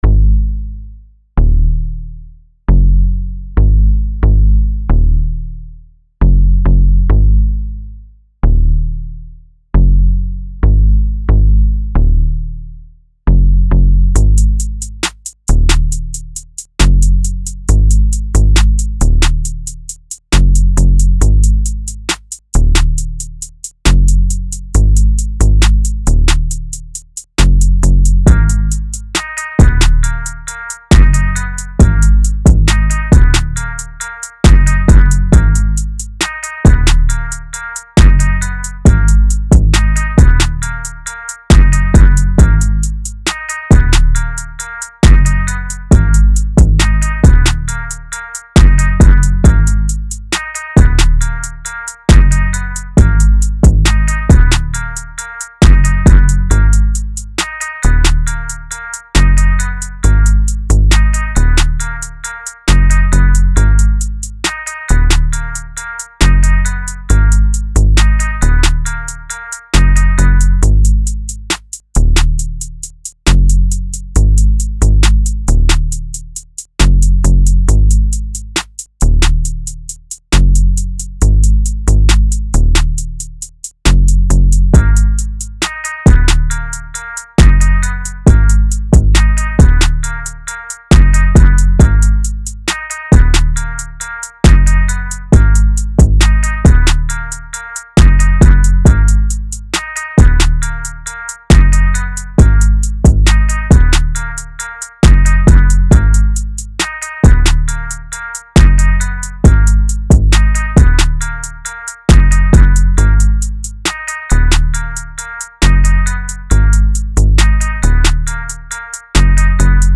The beat is bouncy, the flow is smooth